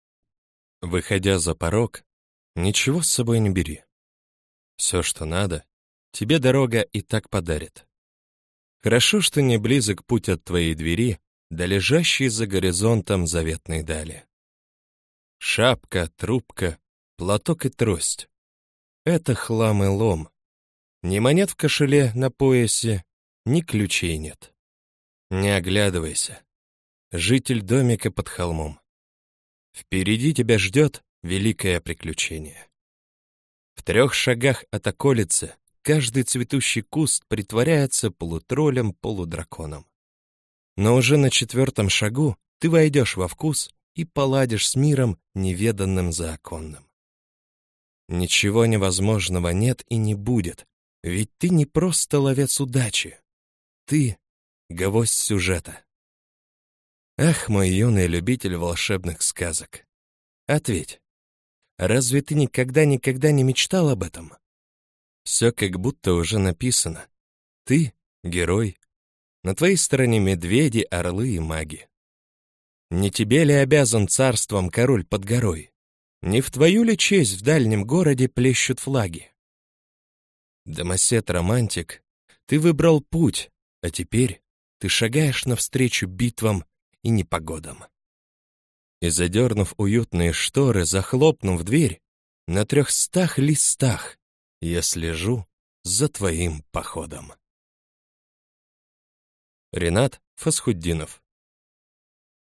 Аудиокнига Рассказы 13. Дорога в никуда | Библиотека аудиокниг